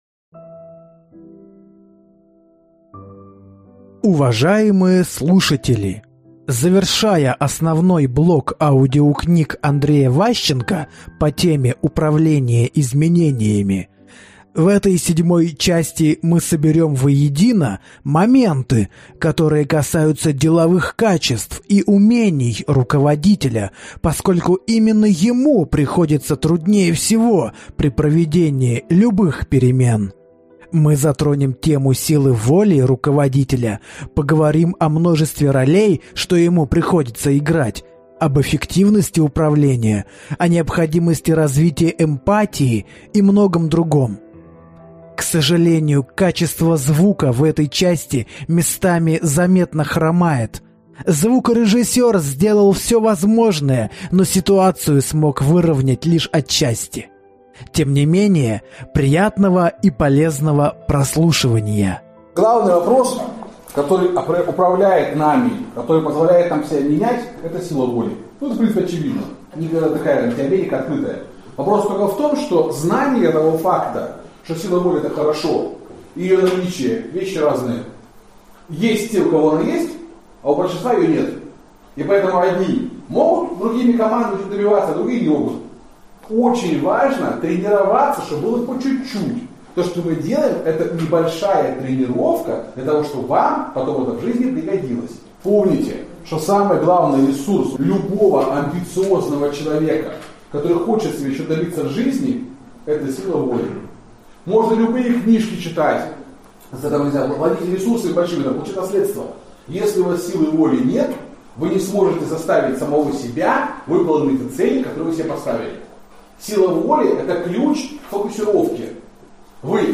Аудиокнига Управление изменениями. Российская практика. Часть 7 | Библиотека аудиокниг